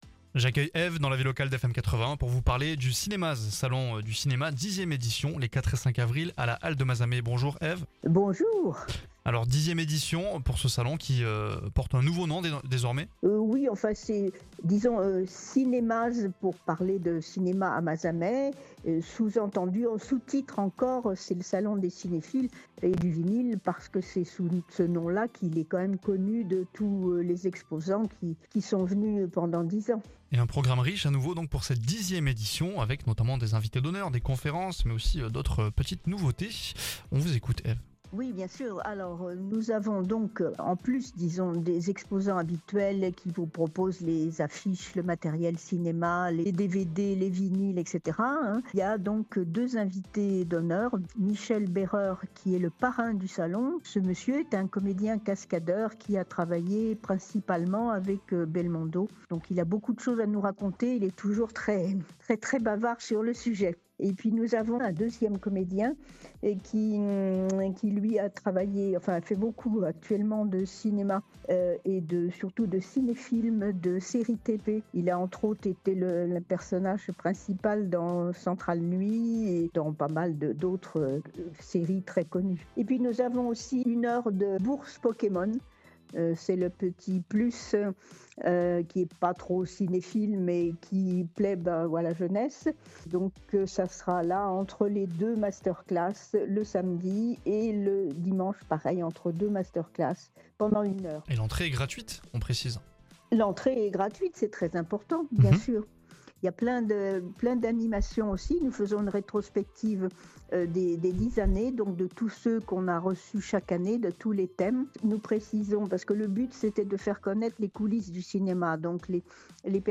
Écoutez l'interview pour tout savoir sur le programme !